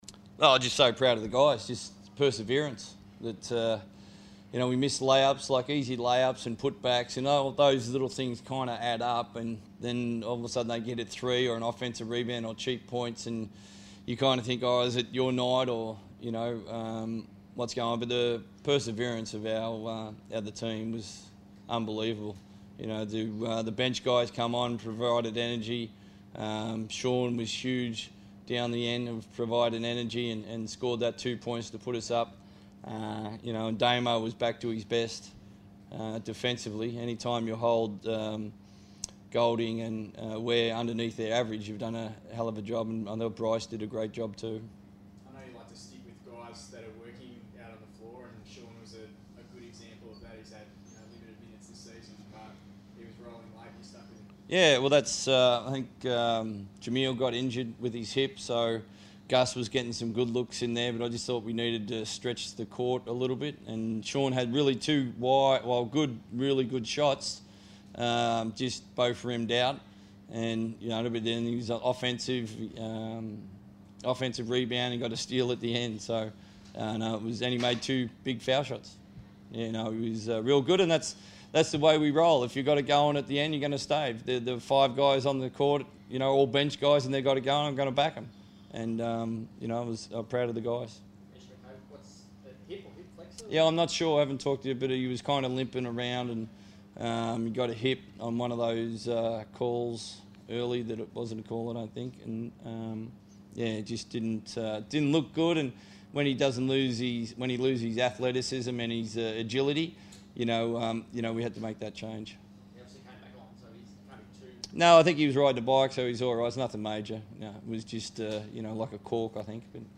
speak to the media following the Perth Wildcats win over Melbourne United.